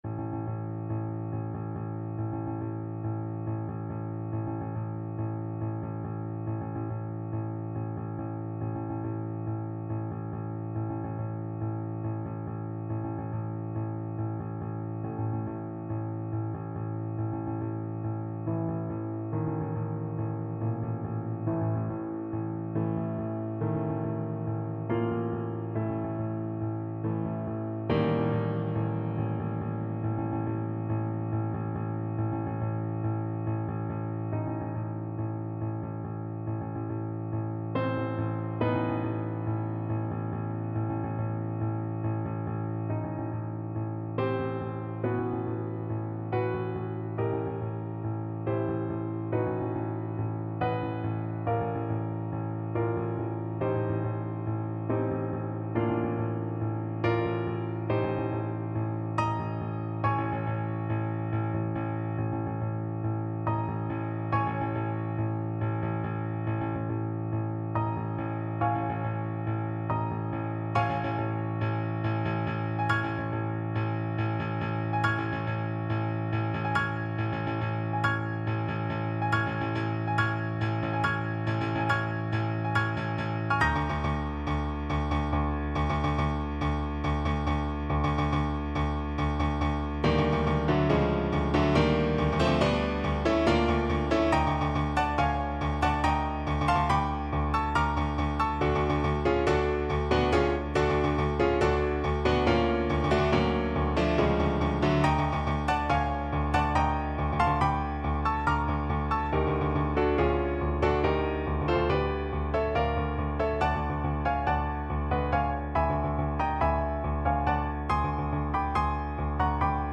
Allegro = 140 (View more music marked Allegro)
5/4 (View more 5/4 Music)
Classical (View more Classical Violin Music)